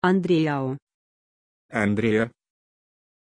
Pronuncia di Andreea
pronunciation-andreea-ru.mp3